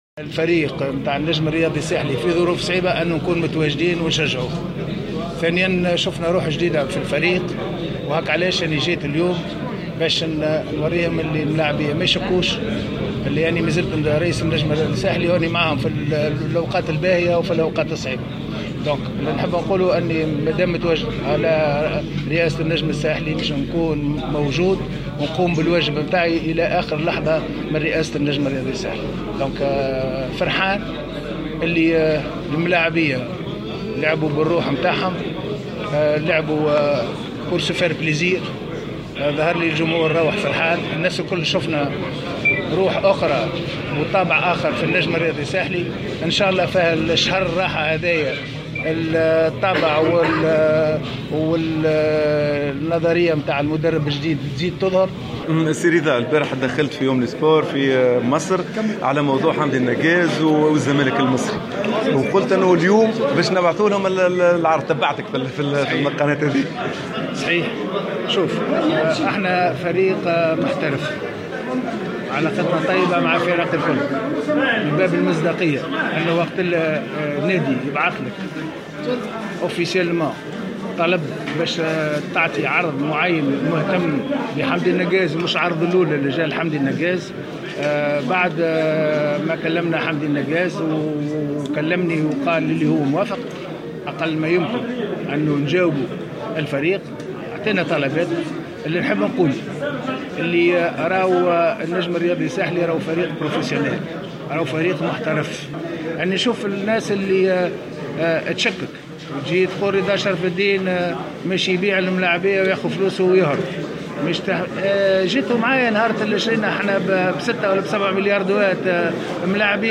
أكد رئيس النجم الساحلي الدكتور رضا شرف الدين في تصريح لجوهرة أف أم إثر المقابلة التي جمعت الفريق بالنادي البنزرتي أن هيئة النجم الساحلي قد راسلت اليوم نظيرتها في الزمالك المصري و حددت شروطها لحسم صفقة إنتقال الظهير الأيمن حمدي النقاز و في صورة التوصل إلى إتفاق بين الهيئتين فإن النقاز قد يغادر في المركاتو الشتوي الحالي.